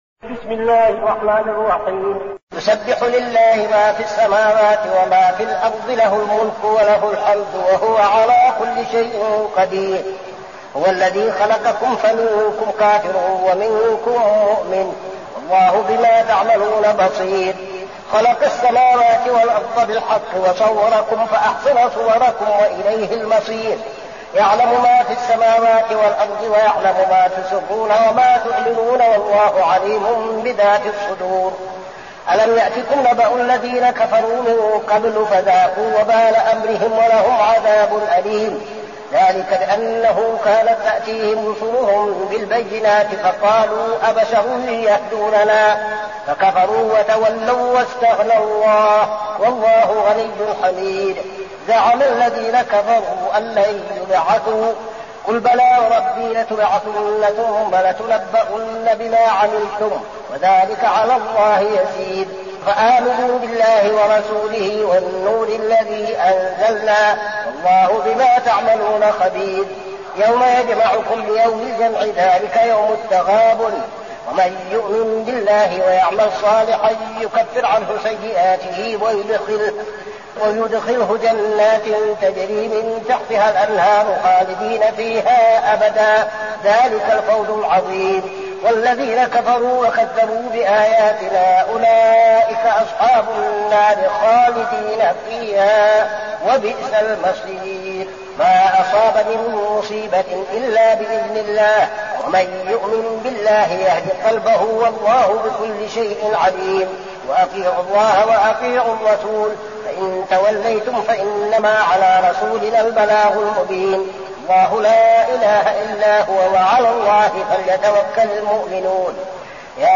المكان: المسجد النبوي الشيخ: فضيلة الشيخ عبدالعزيز بن صالح فضيلة الشيخ عبدالعزيز بن صالح التغابن